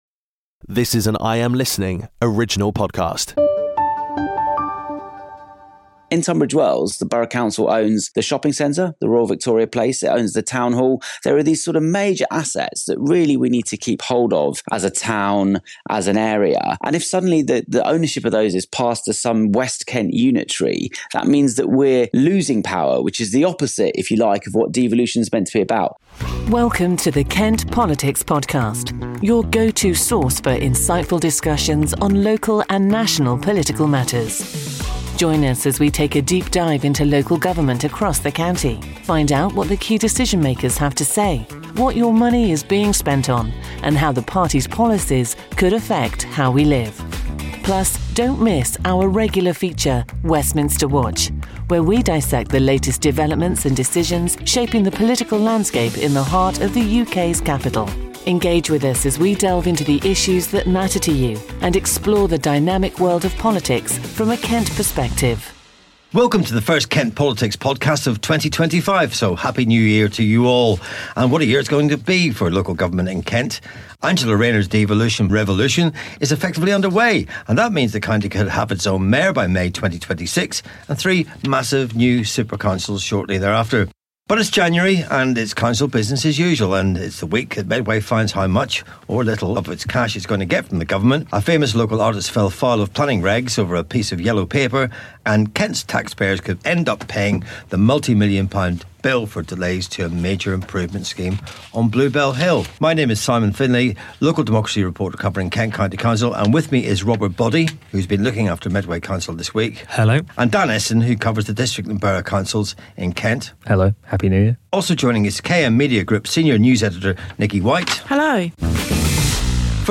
Interview Spotlight with Mike Martin (Liberal Democrat MP): Mike shares his journey from the British Army to Parliament, discusses housing targets, devolution plans, defence priorities post-Brexit, and thoughts on national service reform.